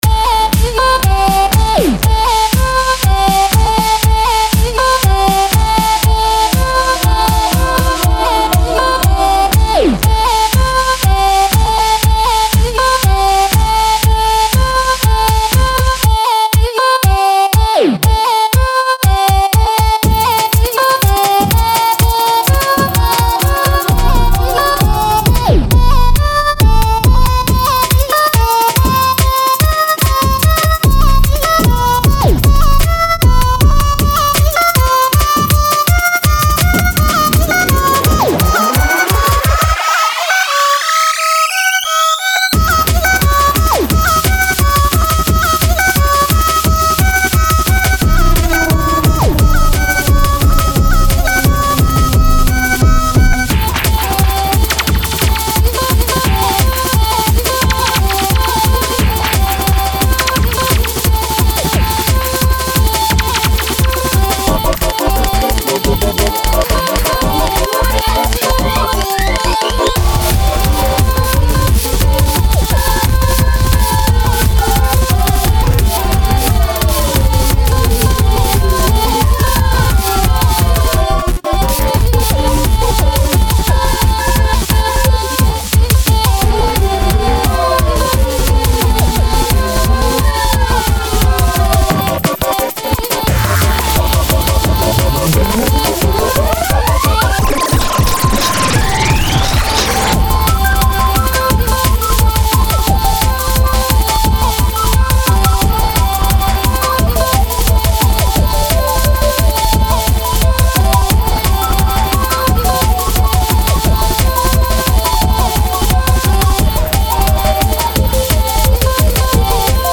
Aaaa - Drum n Bass
Music / Techno
drumnbass techno electronica